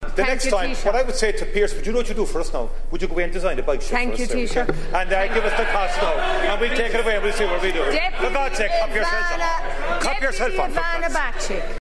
A row has broken out in the Dail between Donegal Deputy Pearse Doherty and the Taoiseach over plans for a new bike shed at the National Maternity Hospital.
But Taoiseach Micheál Martin says the Government is not responsible for every single project undertaken: